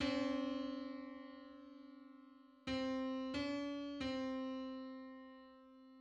Just: 279/256 = 148.95 cents.
Public domain Public domain false false This media depicts a musical interval outside of a specific musical context.
Two-hundred-seventy-ninth_harmonic_on_C.mid.mp3